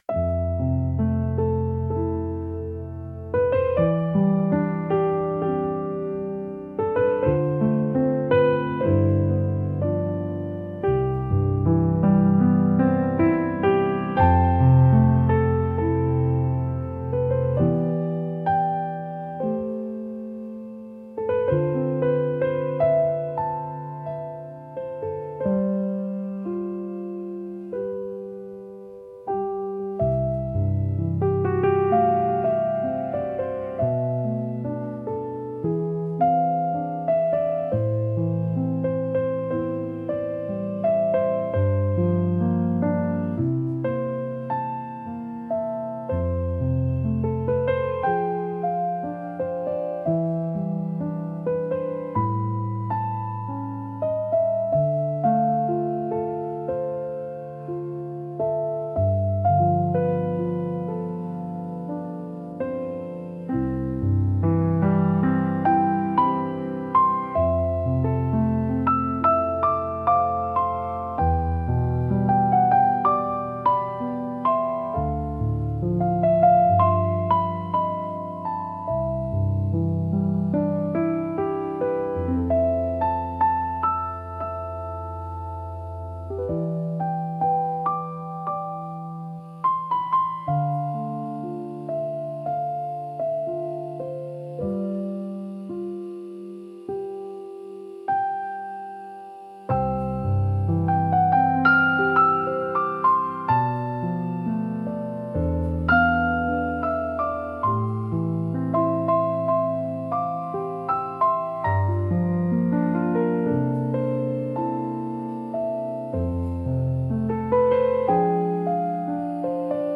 聴く人にやすらぎと温かみを提供し、心を穏やかに整える効果があります。繊細で情感豊かな空気を醸し出すジャンルです。